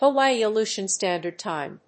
アクセントHawáii‐Aléutian (Stándard) Tìme